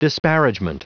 Prononciation du mot disparagement en anglais (fichier audio)
Prononciation du mot : disparagement